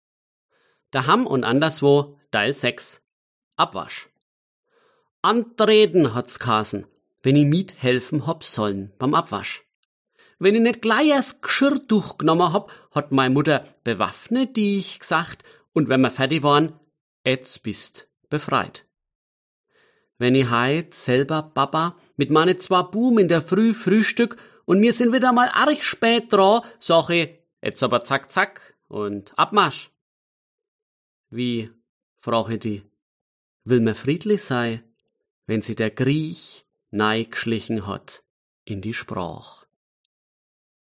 Liebe Freundinnen und Freunde des guten Wortes, liebe Fans des Fränkischen,